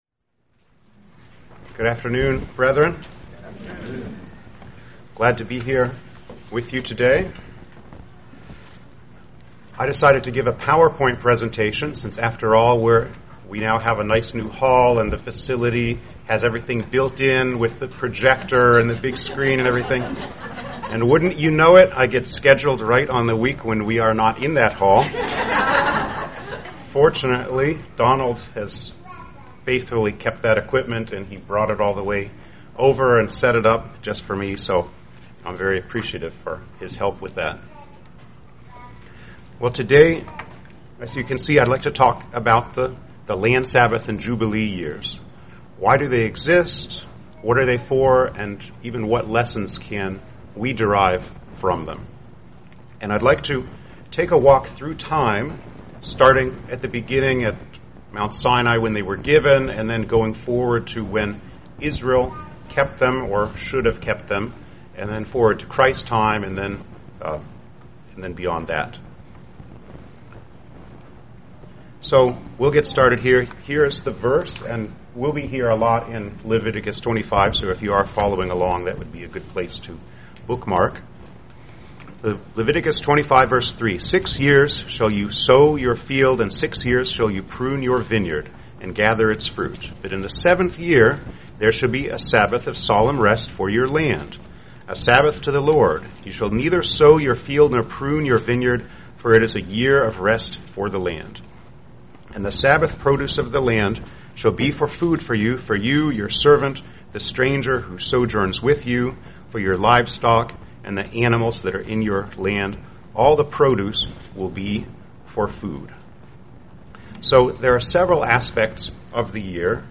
Given in Indianapolis, IN
UCG Sermon Studying the bible?